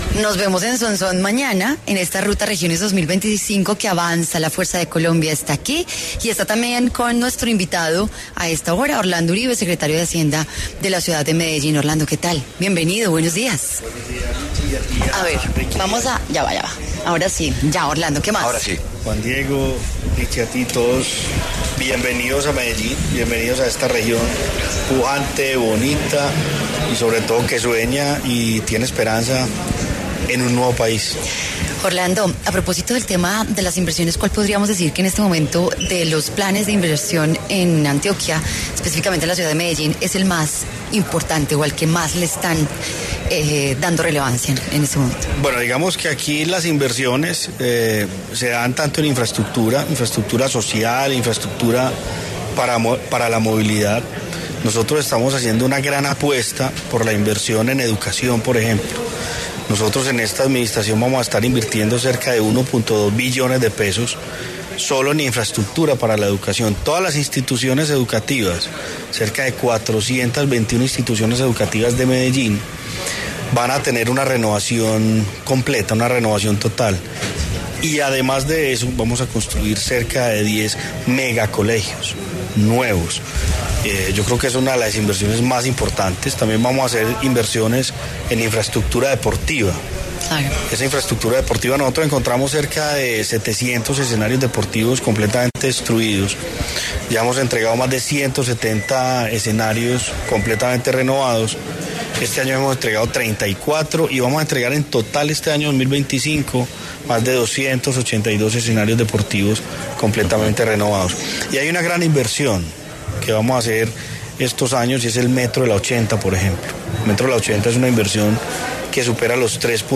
Orlando Uribe, secretario de Hacienda de Medellín, conversó con La W acerca de las diferentes inversiones que están haciendo desde la administración para mejorar la calidad de los ciudadanos.